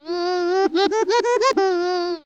dronesay.ogg